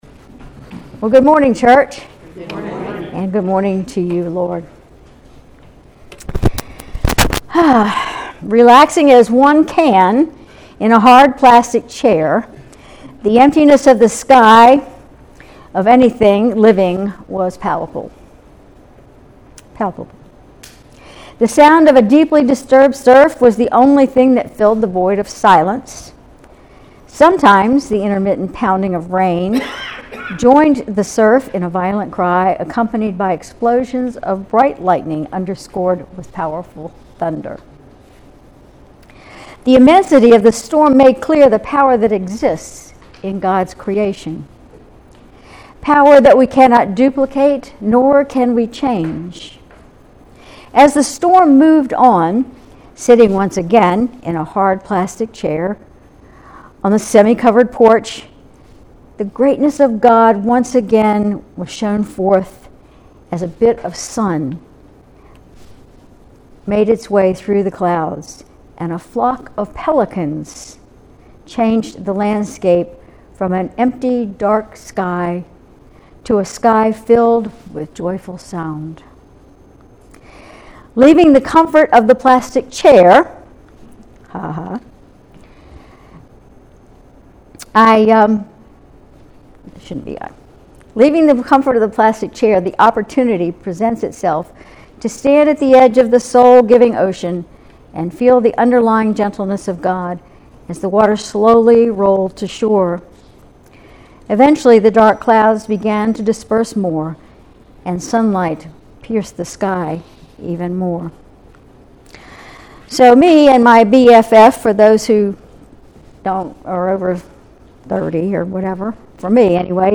Sermon August 11, 2024